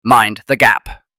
We deliver clear, professional, and neutral-sounding English announcements using a custom-trained AI voice that replicates the style of real British transport systems.